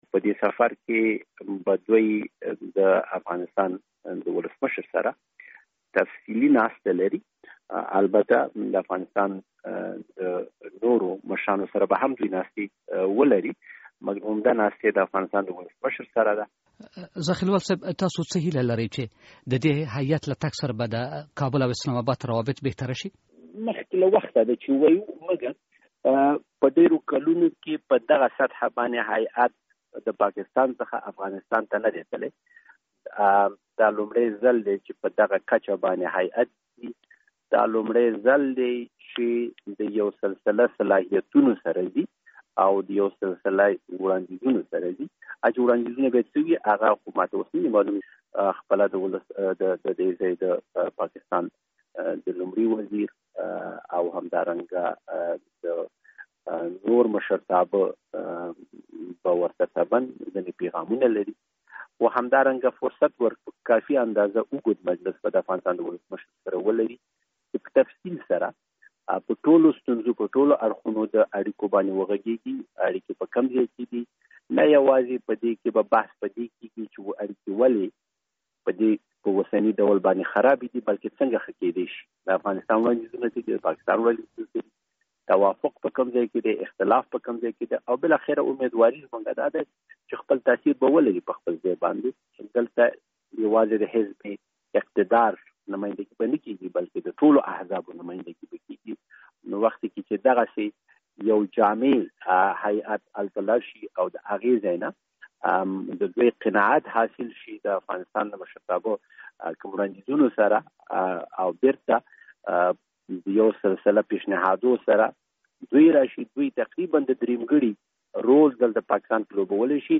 له حضرت عمر زاخېلوال سره مرکه.